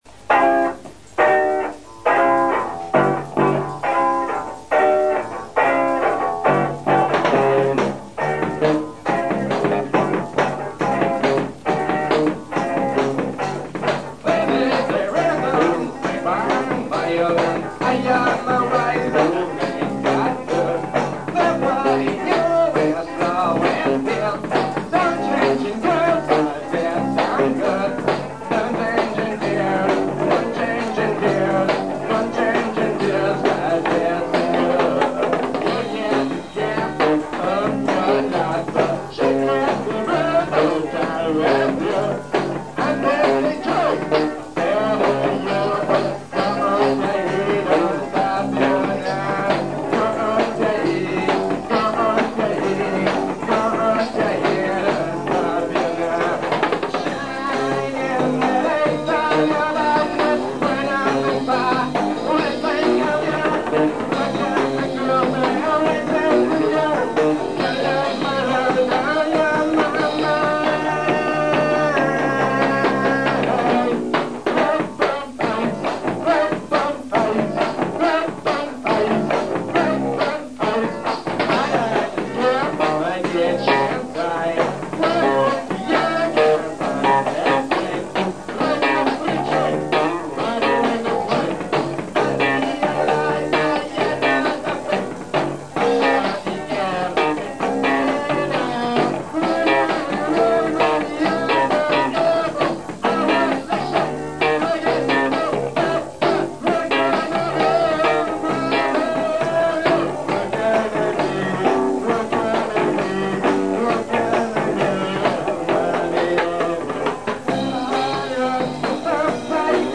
voce, chitarra
basso
batteria
Prove registrate nel Dicembre 1985